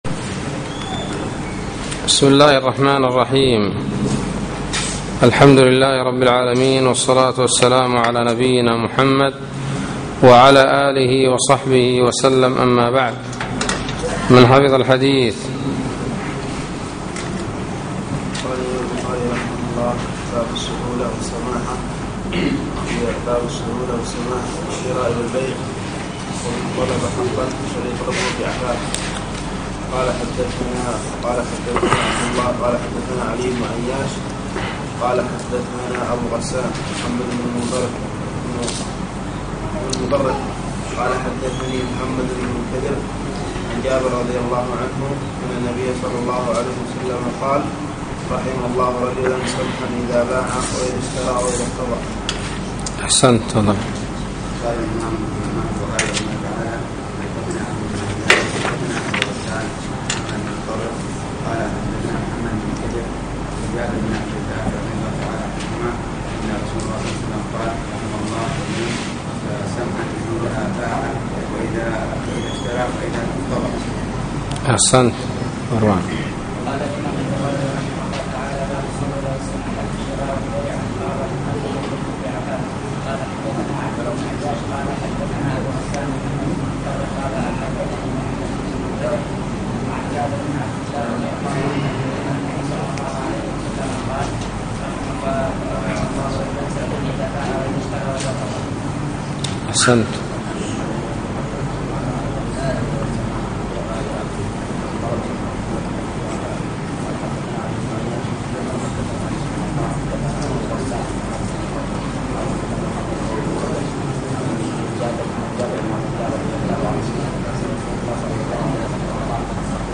الدرس السابع عشر : بَاب: مَنْ أَنْظَرَ مُوسِرًا و بَاب: مَنْ أَنْظَرَ مُعْسِرًا